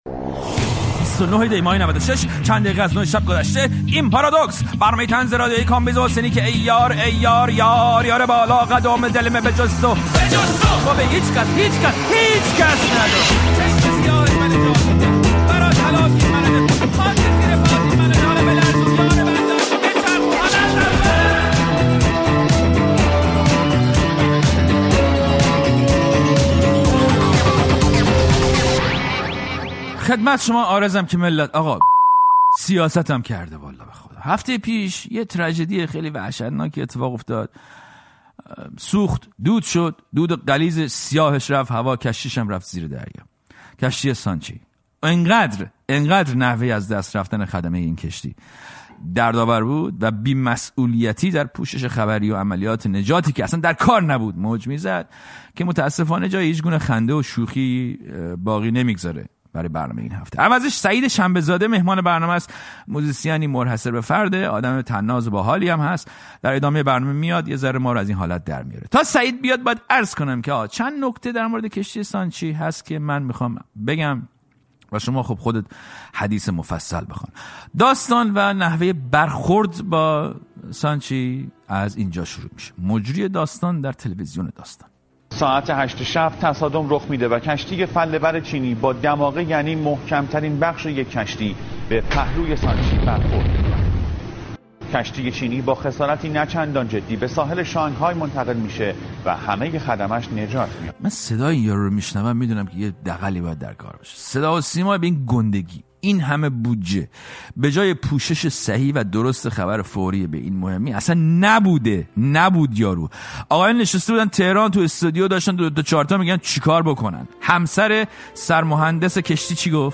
پارادوکس با کامبیز حسینی؛ گفت‌وگو با سعید شنبه‌زاده